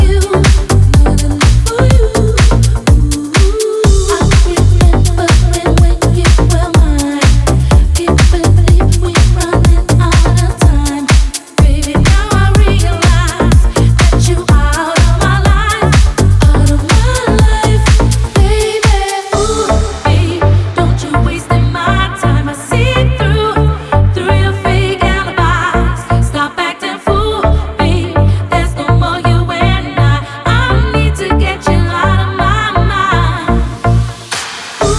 Жанр: Танцевальные / Хаус